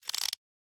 Minecraft Version Minecraft Version snapshot Latest Release | Latest Snapshot snapshot / assets / minecraft / sounds / item / spyglass / use.ogg Compare With Compare With Latest Release | Latest Snapshot